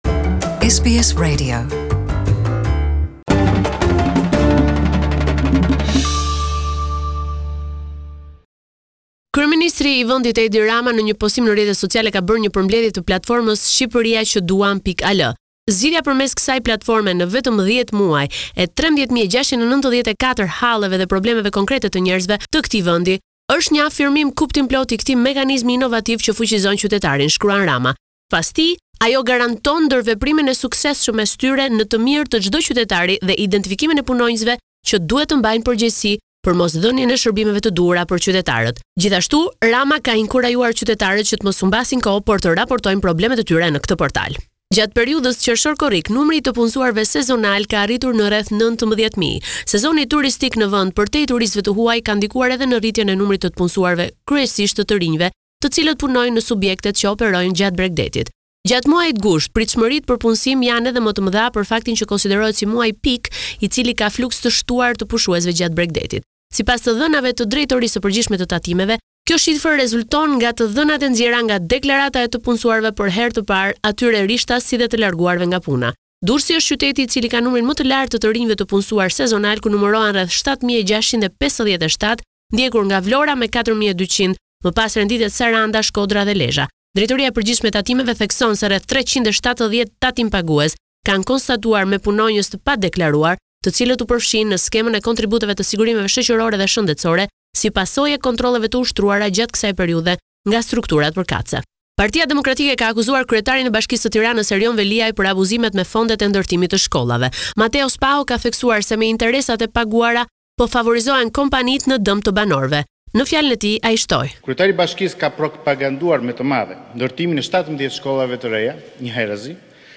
This is a report summarising the latest developments in news and current affairs in Albania